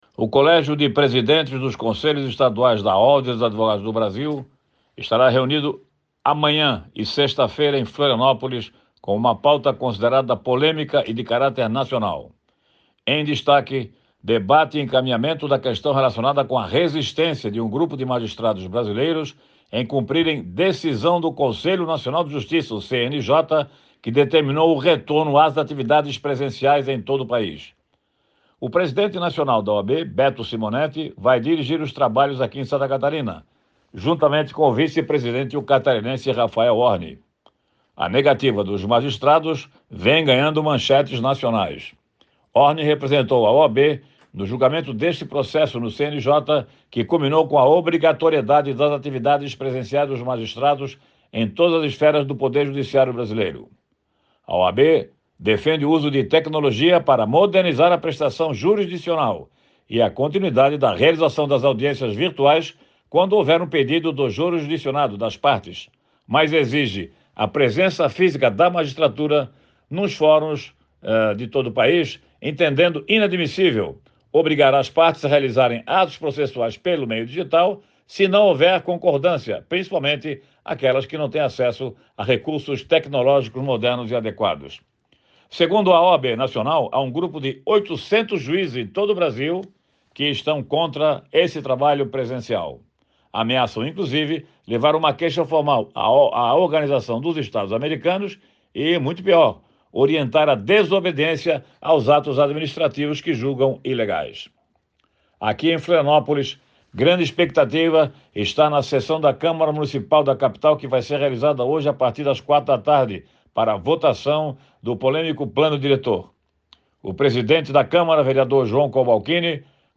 Em outro destaque no comentário, o jornalista fala da grande expectativa para a sessão que ocorrerá nesta quarta-feira, às 16h, na Câmara Municipal de Florianópolis. O tema principal será a votação para aprovação do Plano Diretor da Capital.